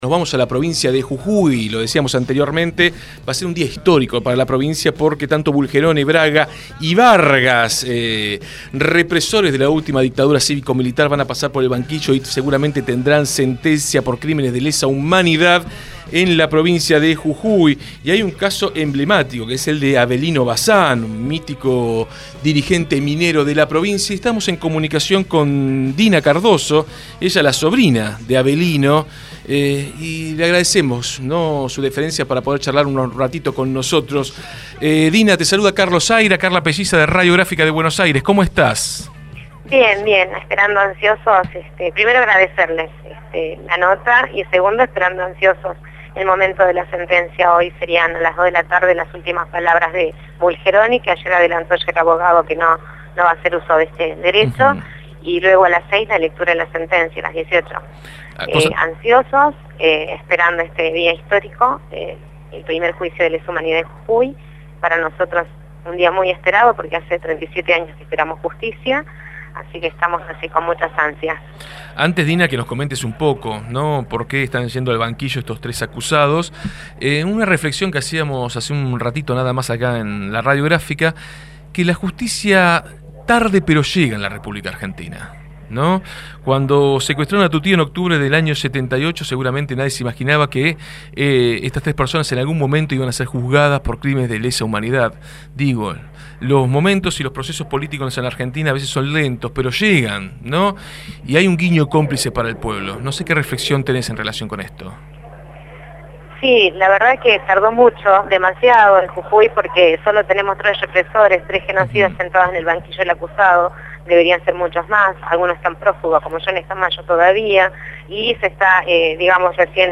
fue entrevistada en Desde el Barrio.